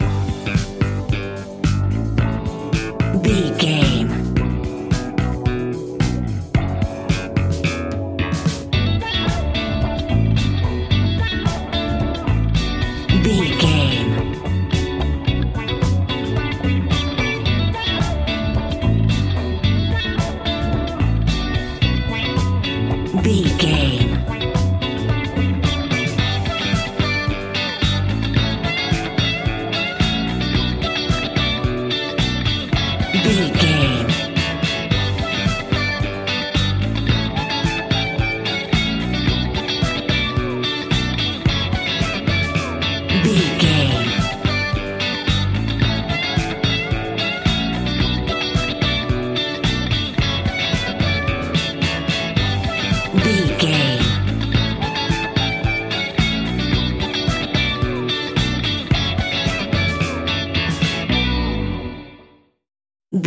Ionian/Major
house
synths
techno
trance